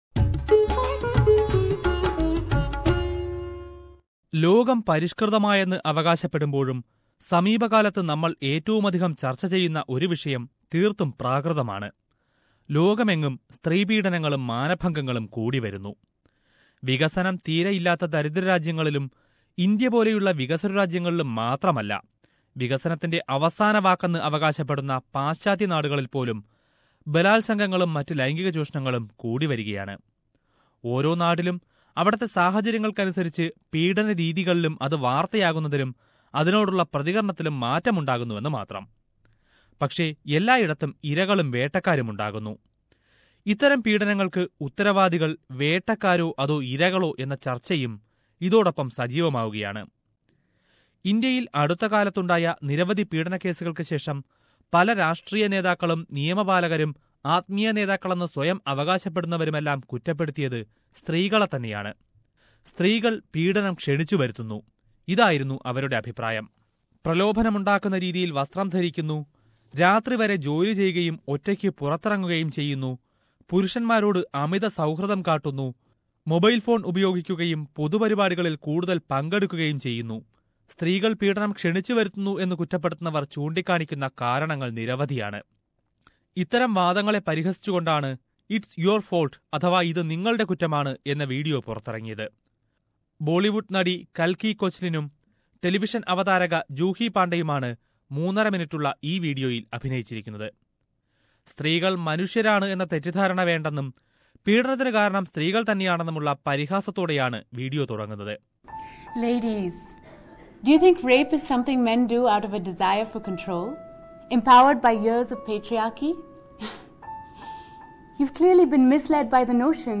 A video which satirises the notion that women are to be blamed in rape cases goes viral on social media websites. Let us a listen to a report about that video...